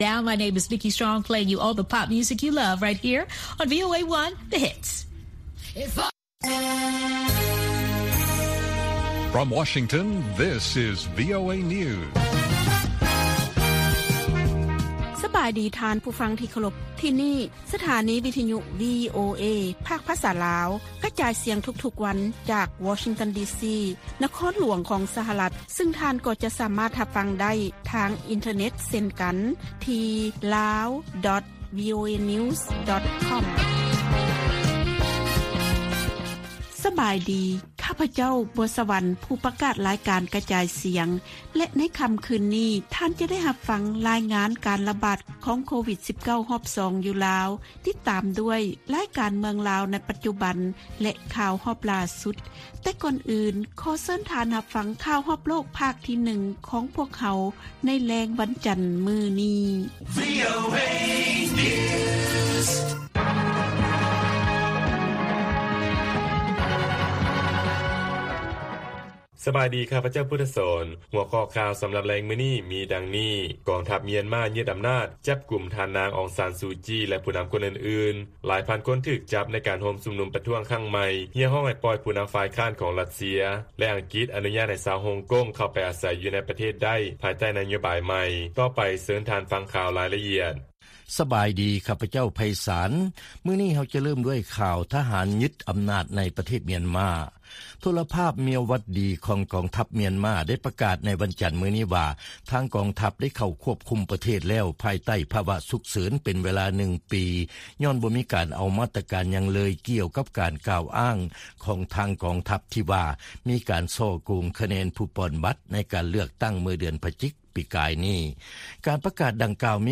ວີໂອເອພາກພາສາລາວ ກະຈາຍສຽງທຸກໆວັນ.